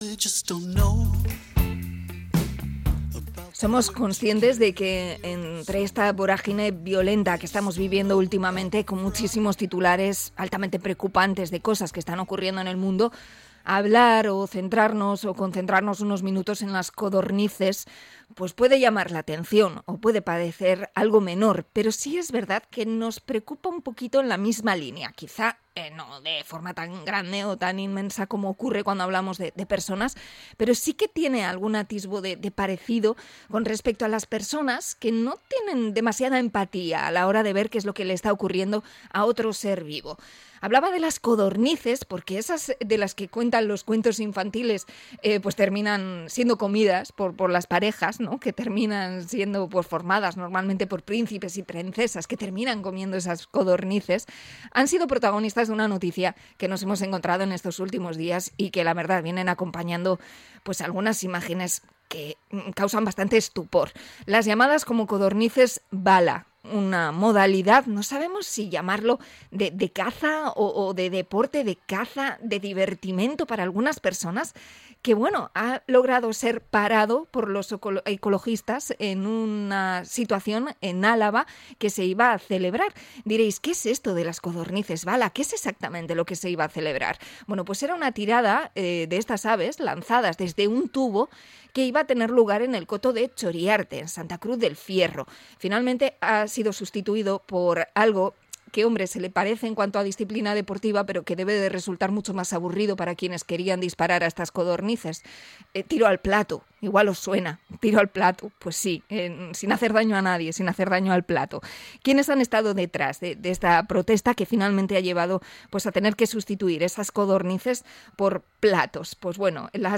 Entrevista a la asociación Haiekin sobre el maltrato tras las codornices bala